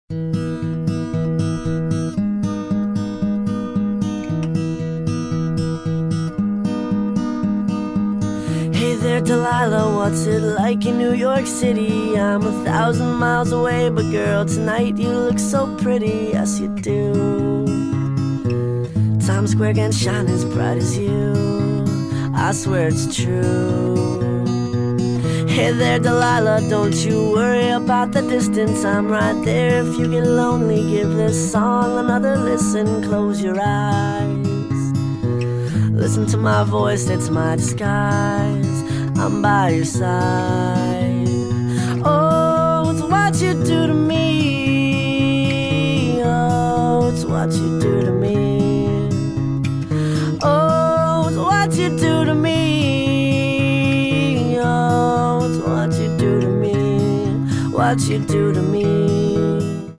sped up